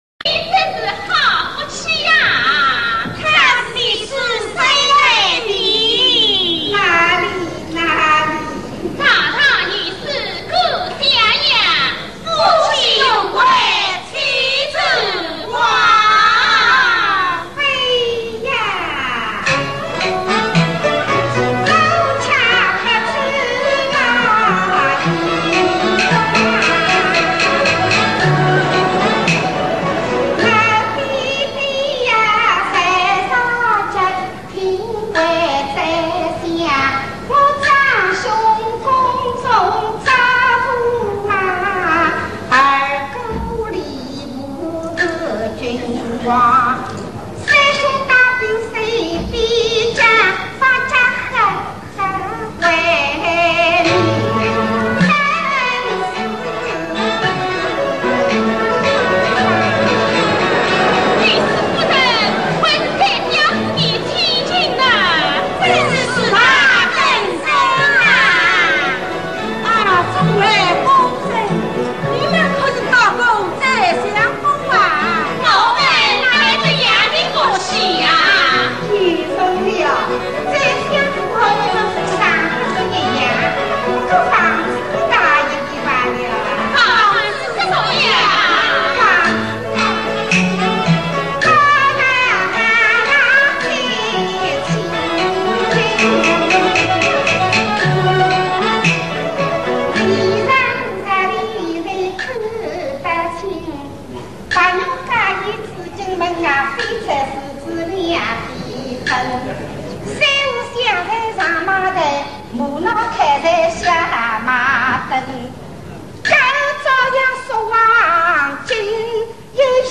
[9/24/2007]精彩锡剧:《珍珠塔-我却不沾老爷光》(王彬彬、汪韵芝)